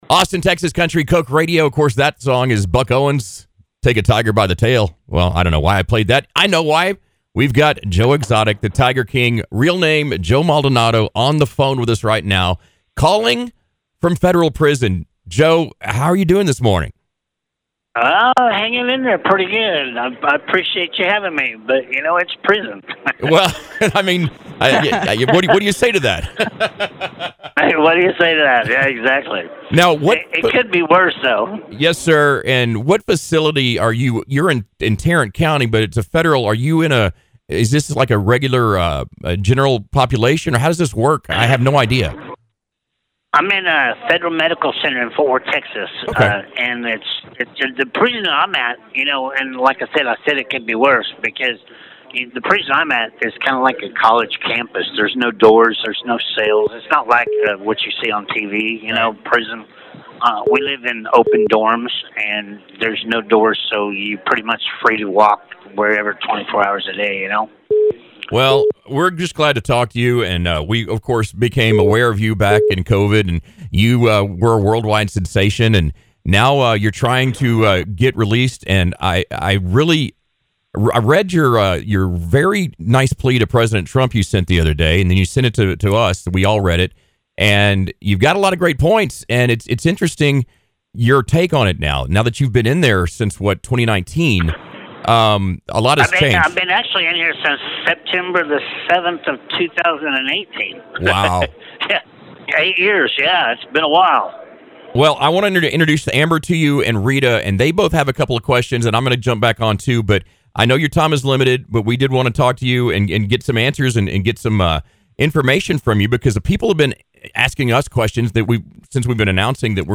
What started as a half joking Valentine’s idea somehow turned into an actual phone call with the one and only Tiger King himself.